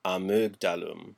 Ääntäminen
Synonyymit vulve Ääntäminen France: IPA: /a.mɑ̃d/ Haettu sana löytyi näillä lähdekielillä: ranska Käännös Ääninäyte 1. amygdalum {n} 2. amygdala {f} Suku: f .